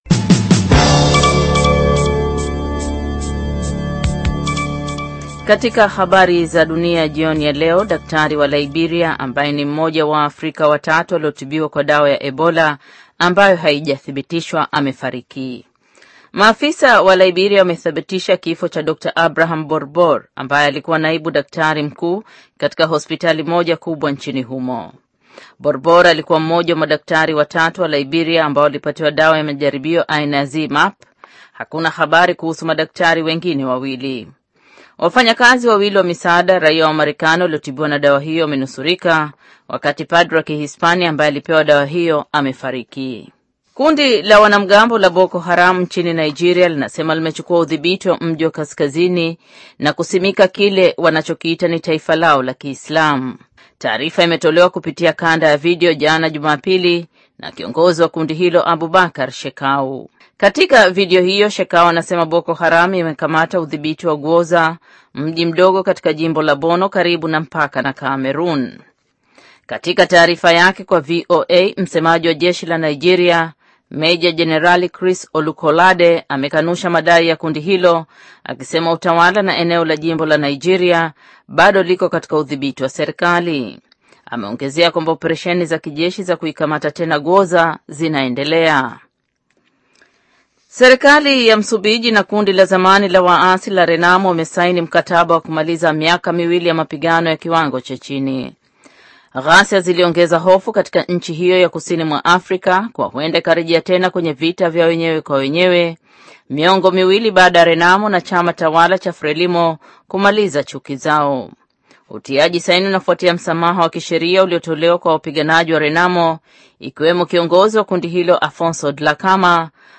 Taarifa ya habari - 6:04